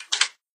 Lock.ogg